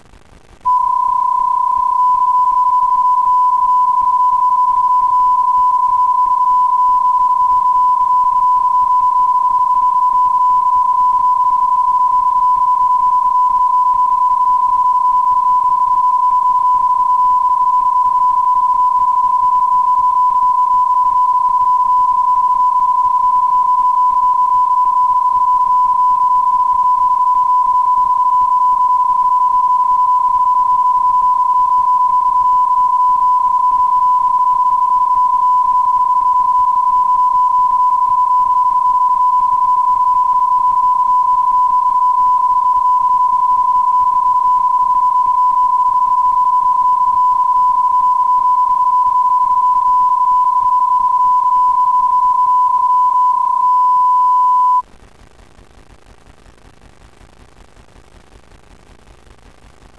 Ecco di seguito come suonano i vari modi:
bpsk31
bpsk31A.wav